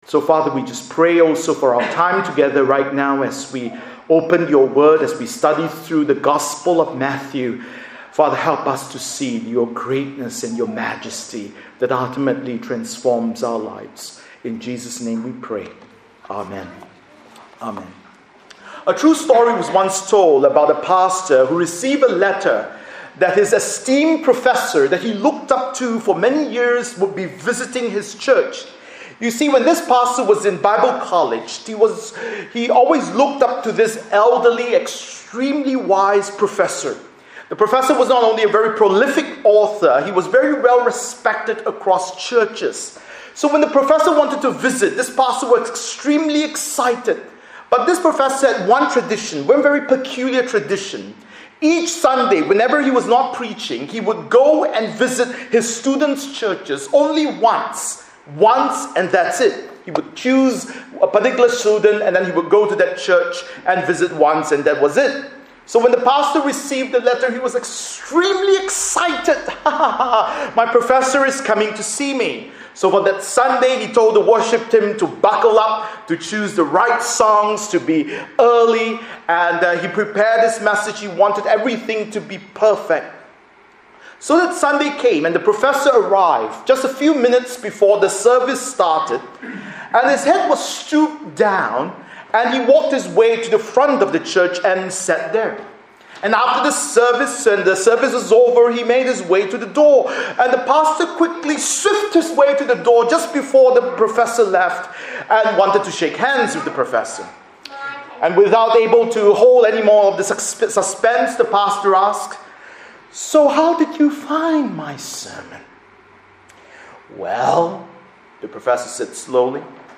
Bible Text: Matthew 3:13-17 | Preacher